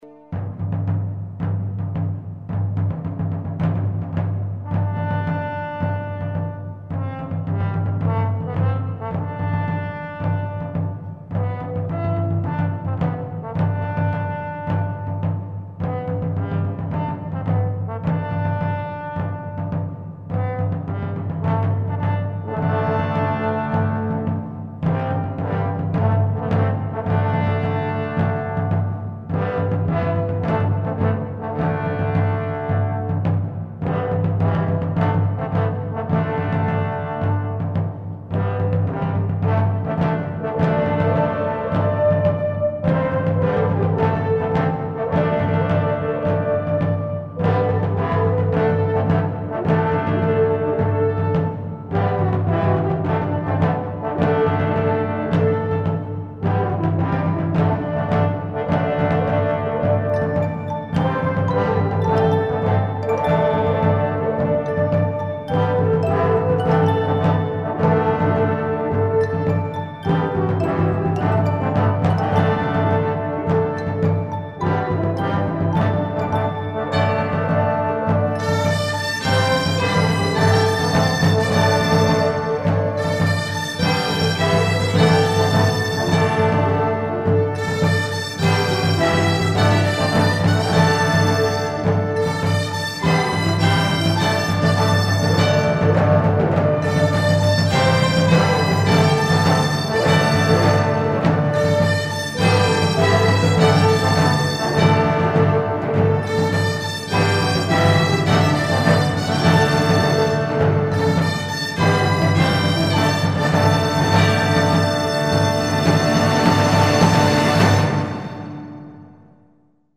Metales, Dolçaines, Electrónica y Percusión